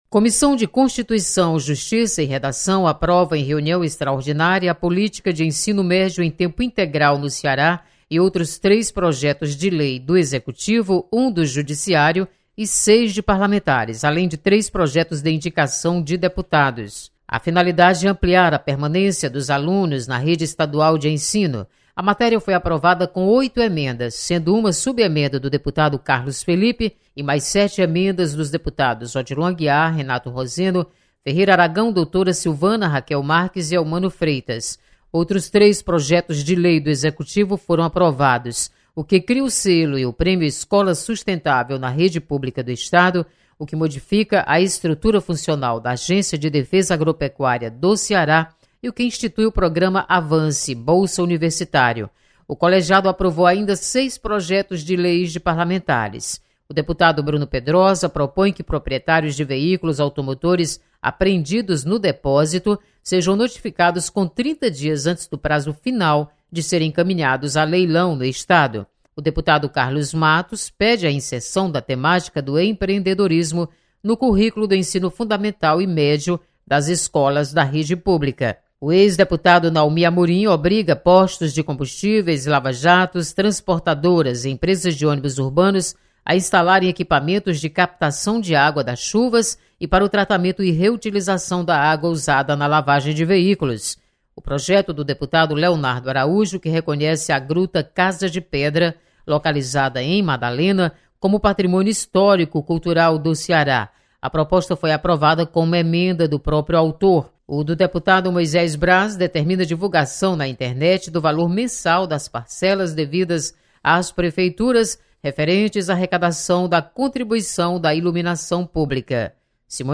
Aprovada Política de Ensino Médio em Tempo Integral na CCJ. Repórter